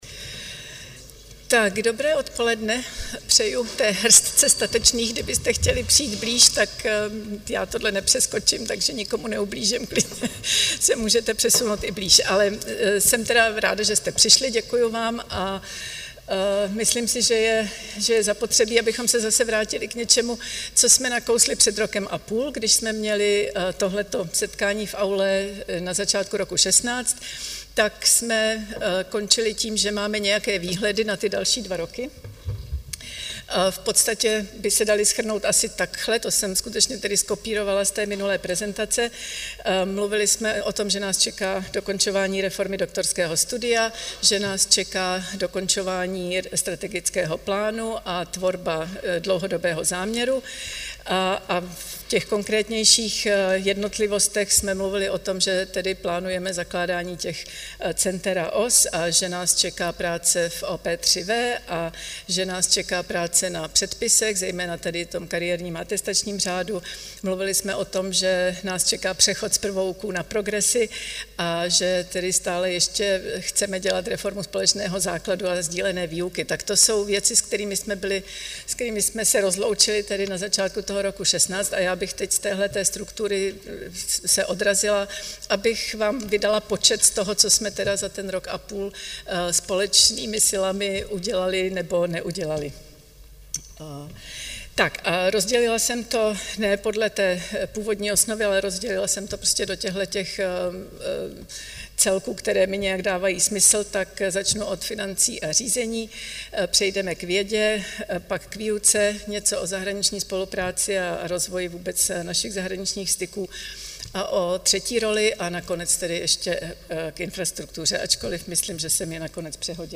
Byl zveřejněn audiozáznam ze setkání děkanky s akademickou obcí
Společná diskuse se konala 14. června 2017.